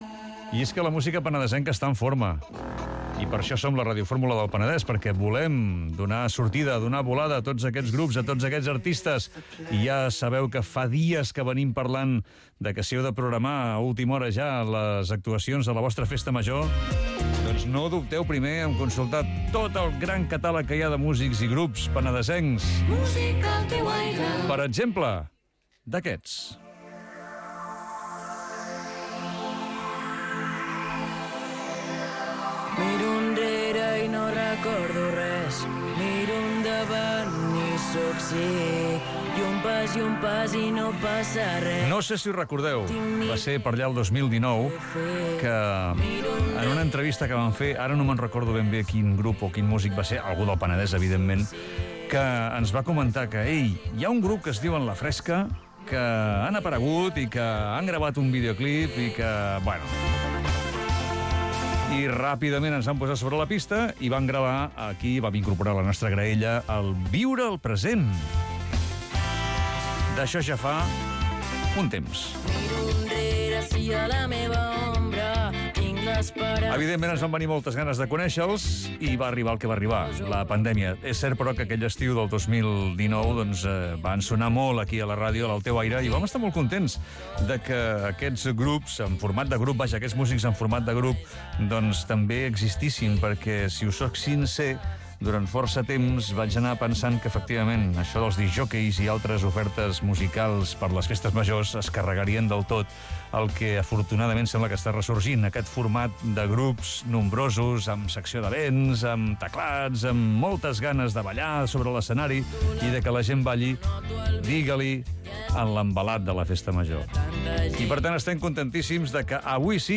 Radiofórmula
Entrevista al grup: La fresca 19/5/23